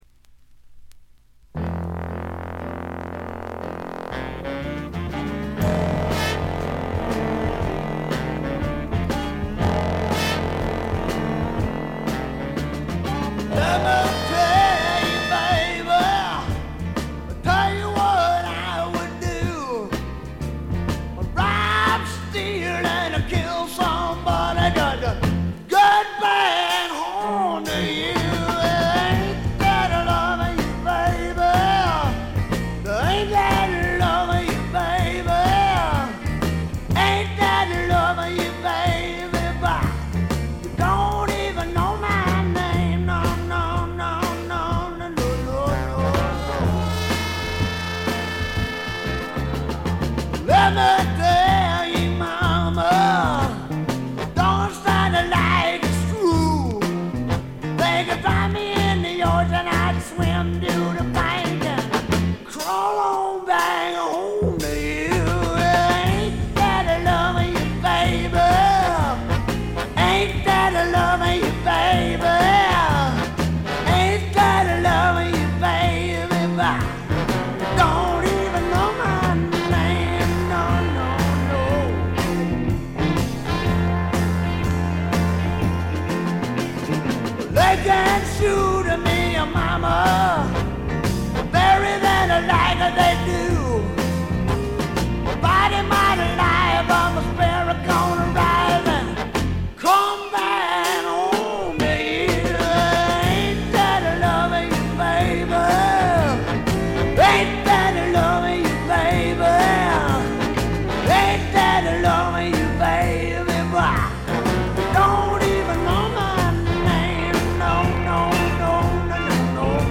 60年代的なポップな味付けを施しながらも、ねばねばなヴォーカルがスワンプど真ん中の直球勝負で決めてくれます。
試聴曲は現品からの取り込み音源です。